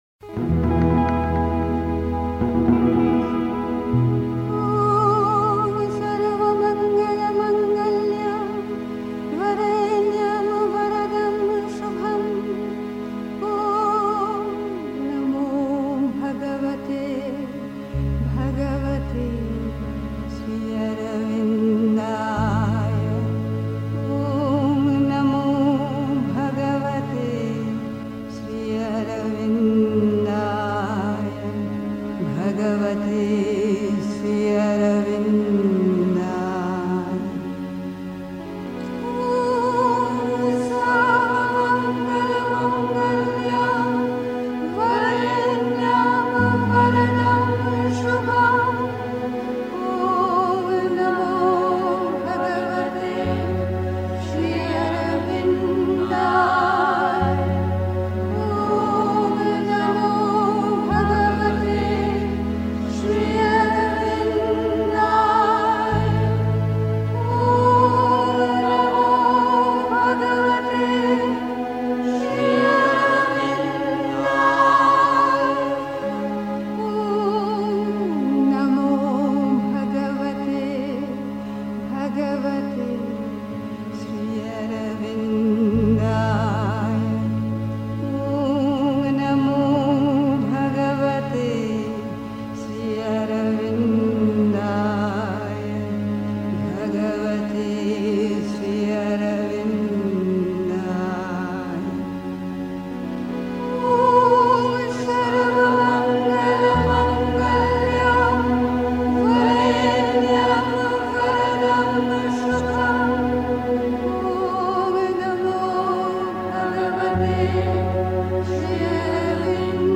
Selbstbeherrschung ist die größte Errungenschaft (Die Mutter, CWM Vol. 14, p. 256) 3. Zwölf Minuten Stille.